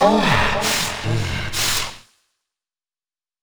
Techno / Voice / VOICEFX196_TEKNO_140_X_SC2(R).wav